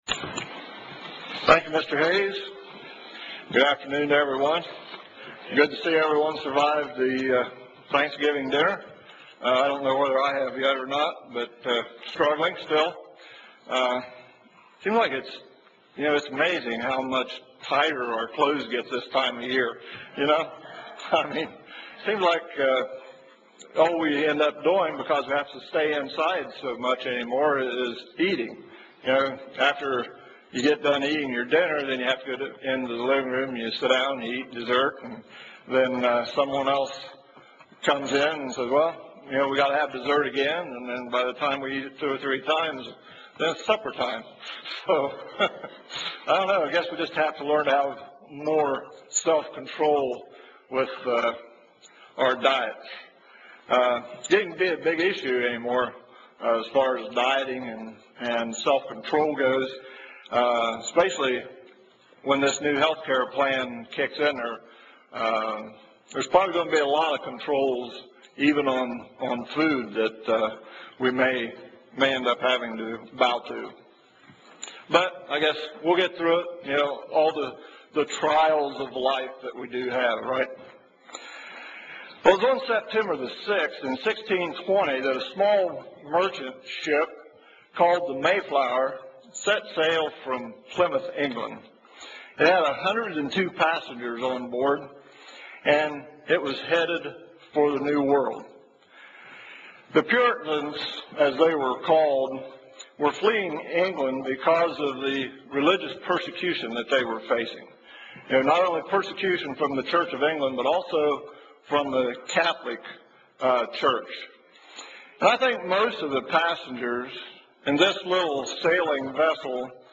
UCG Sermon Studying the bible?
Given in Columbus, OH